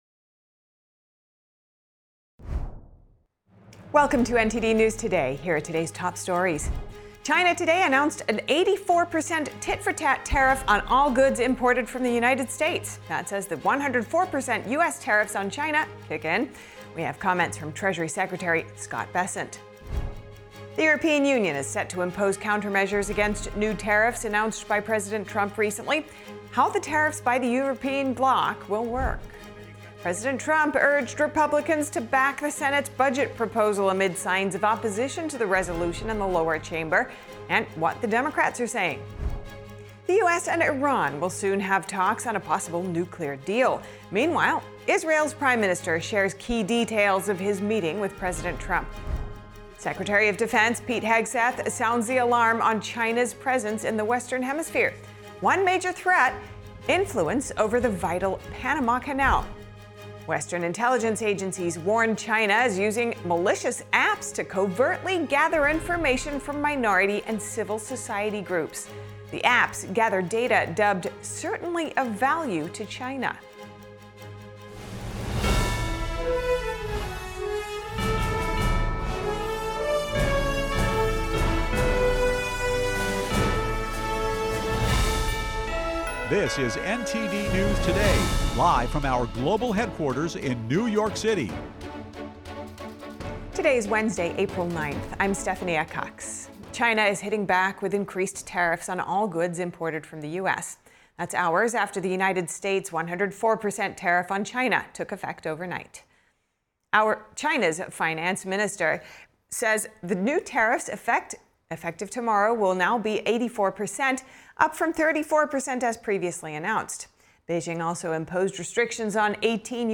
NTD-News-Today-Full-Broadcast-April-9-audio-converted.mp3